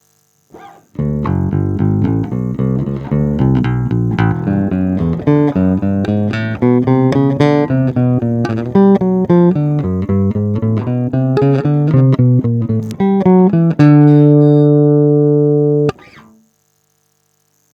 No asi klasický jazz bass J Posuďte sami z nahrávek, které jsou provedeny rovnou do zvukovky bez úprav.
Kobylkový a tonovka o třetinu stažená
V ukázkách ti trochu sice něco "cvrčí" v pozadí a občas přebuzuje, ale tvoje nahrávky se mi líbí víc než originál z toho videa, kde mi to připadá nahrané dost nechutně s "mid scooped" charakterem (schválně si to poslechněte a porovnejte).